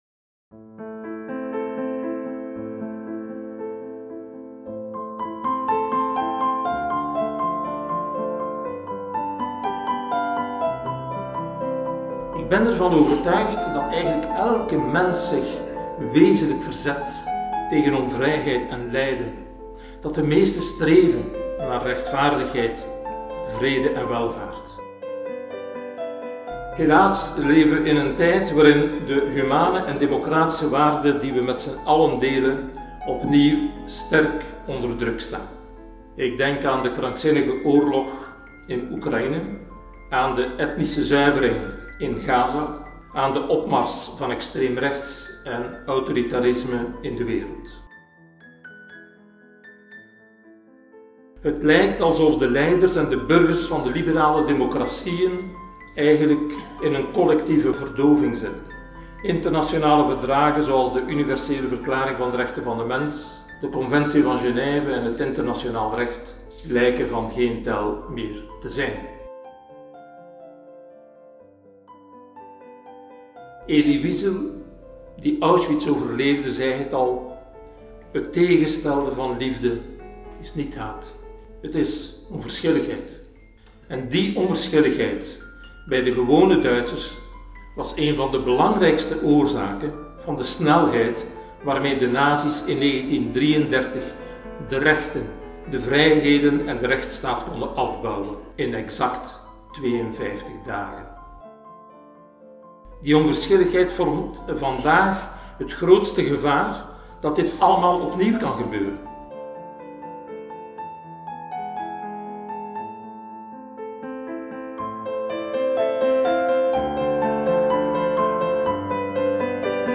uitreiking_pr_vrijz_hu_2025.mp3